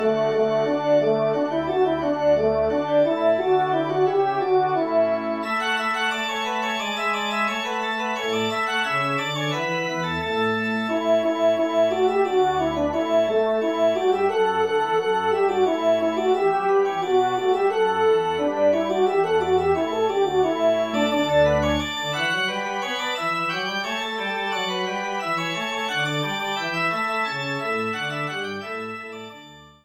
Version Trompes et/ou Cors en Ré & Orgue :
ENSEMBLE (1ère Gavotte)